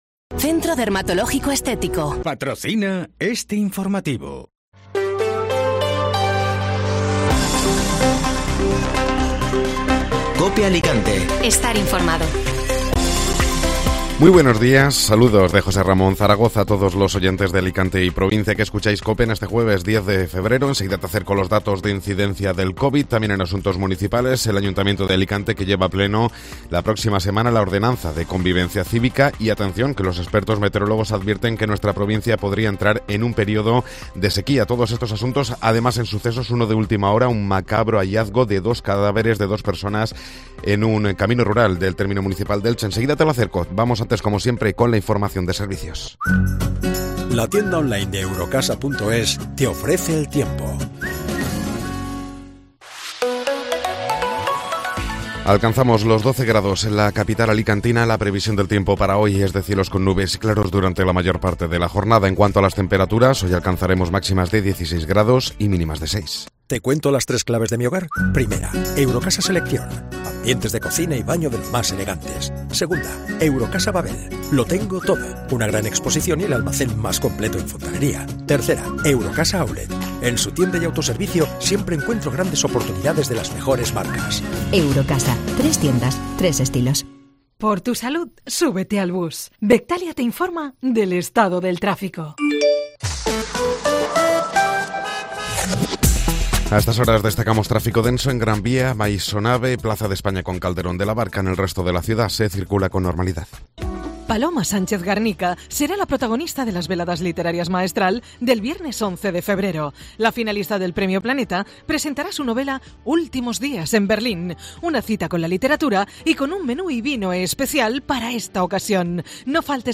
Informativo Matinal (Jueves 10 de Febrero)